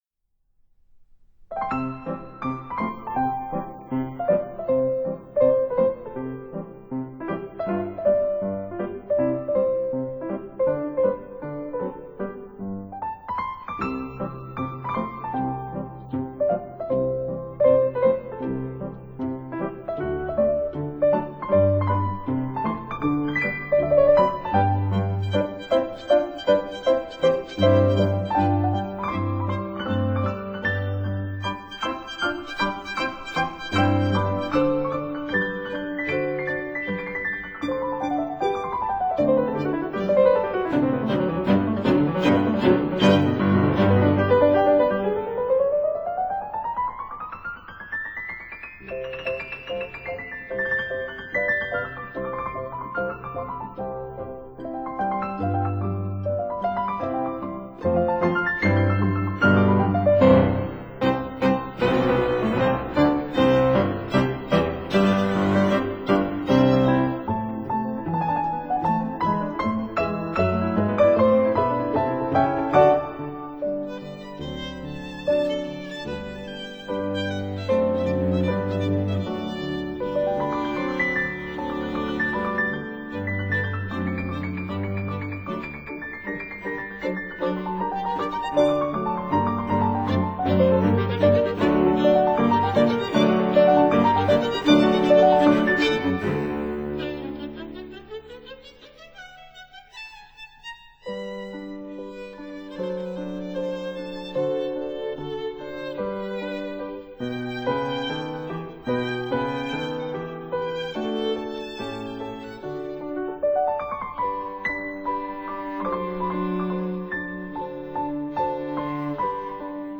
violin
cello
piano